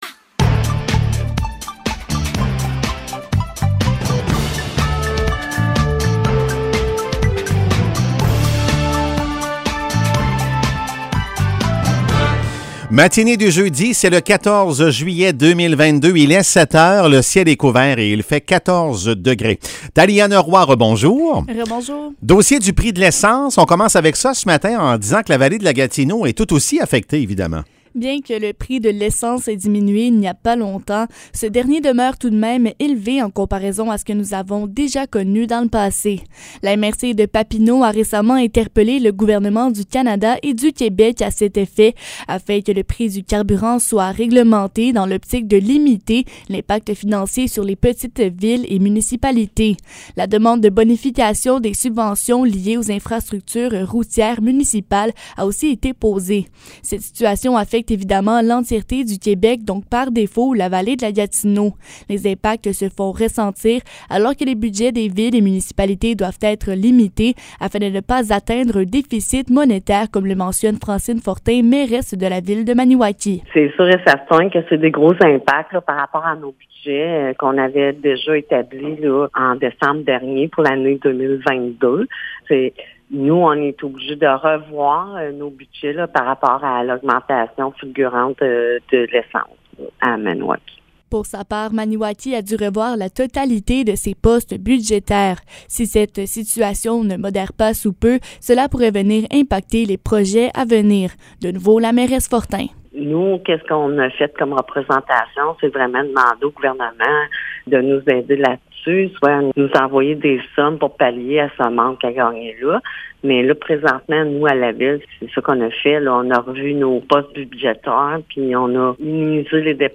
Nouvelles locales - 14 juillet 2022 - 7 h